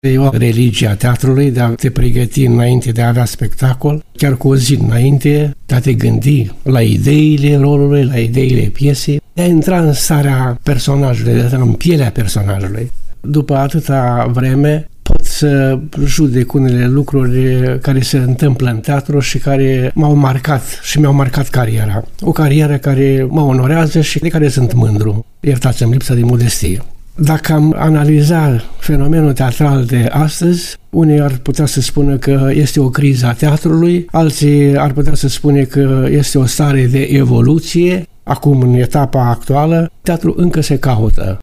Dionisie Vitcu a vorbit, în una dintre edițiile emisiunii Spectacolul Artei, la Radio Iași, despre rigorile meseriei de actor, rigori pe care le-a respectat în toată cariera sa.